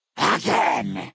11 KB Category:Fallout: New Vegas creature sounds 1
FNV_GenericFeralGhoulAttack_Again.ogg